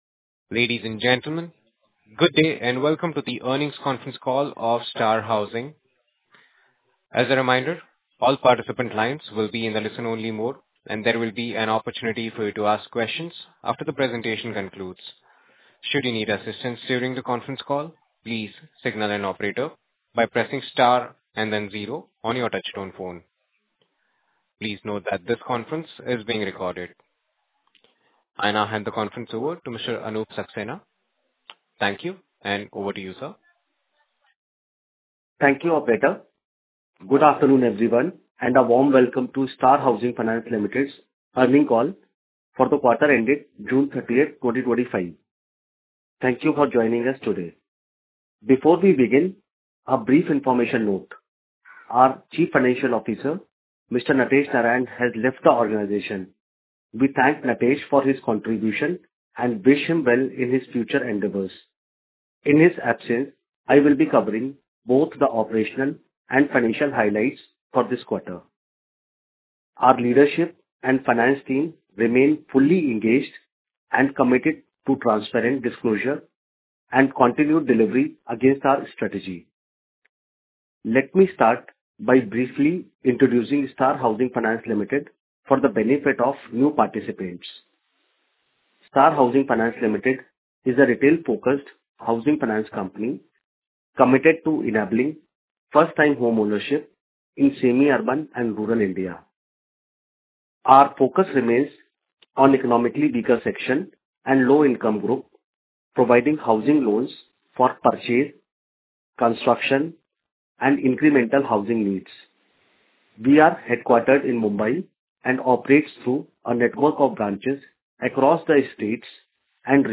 Earnings Call
Q1-Earnings-Call-Recording.mp3